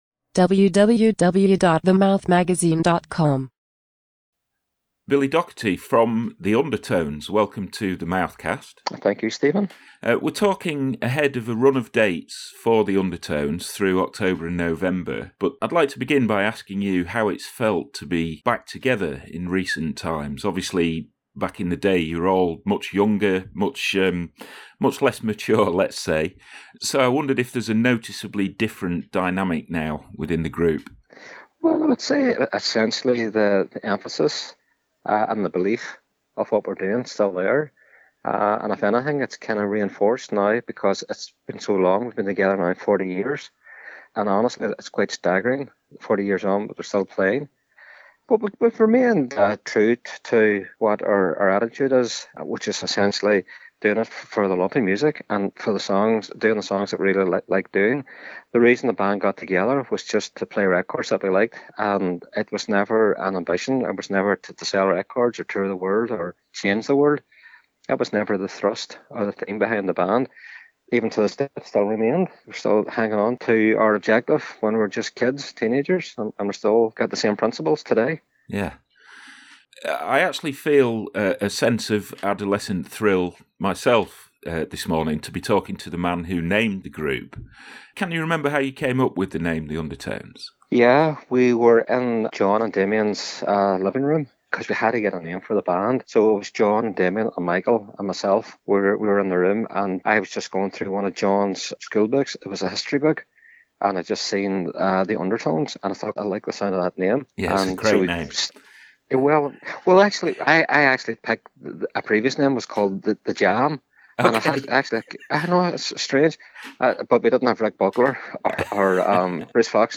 Drummer Billy Doherty – also, crucially, the man who named the band – features in this new edition of The Mouthcast ahead of a run of UK dates. He talks about the impact of TEENAGE KICKS, looks back at naming The Undertones, and also discusses the political / social situation in Northern Ireland.